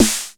Index of /90_sSampleCDs/Masterbits - TR-Minator/VS-SNARES